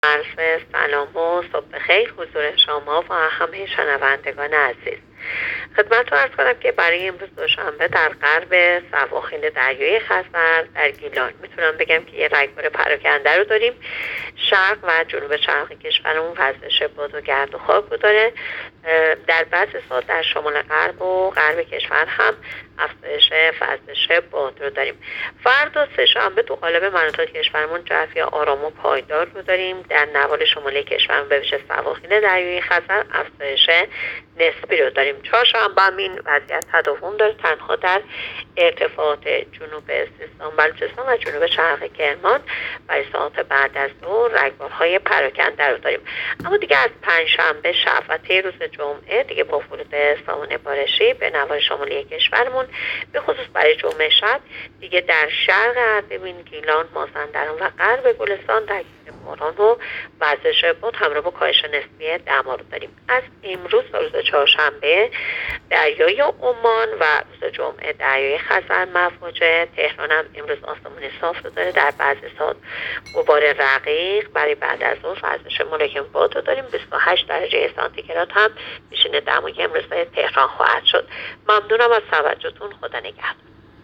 گزارش رادیو اینترنتی پایگاه‌ خبری از آخرین وضعیت آب‌وهوای ۱۴ مهر؛